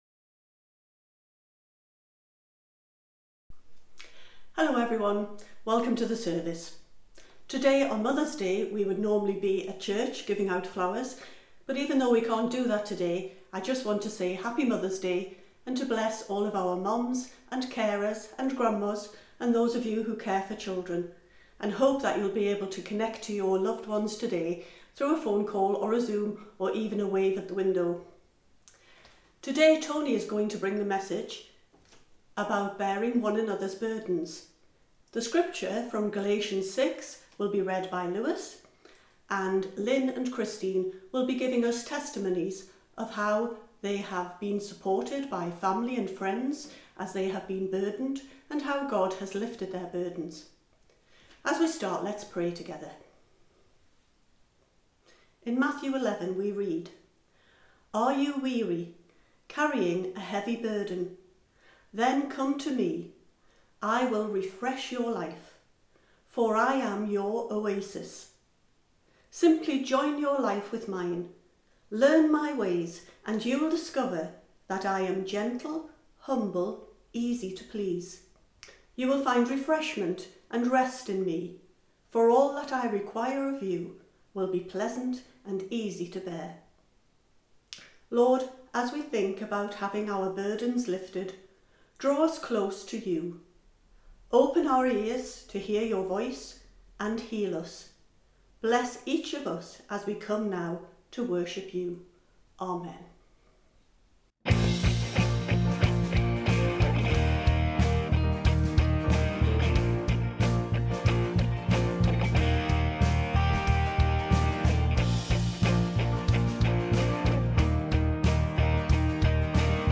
Sermon
Morning Service